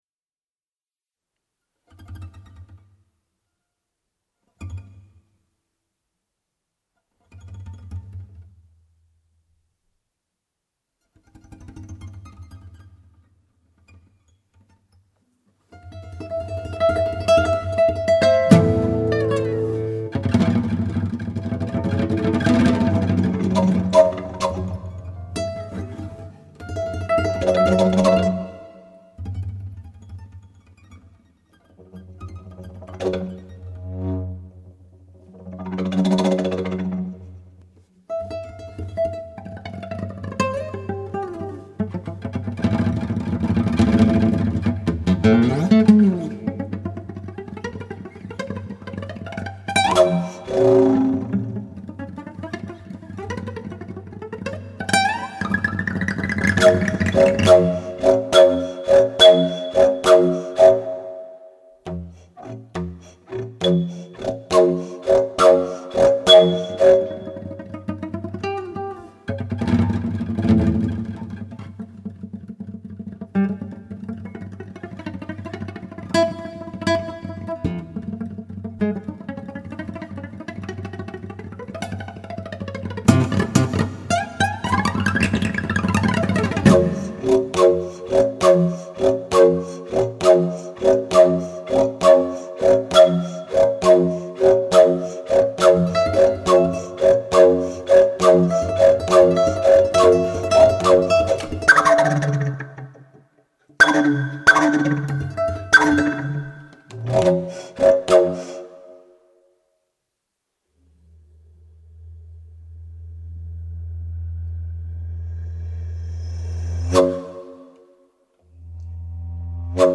Guitar and Blockflöte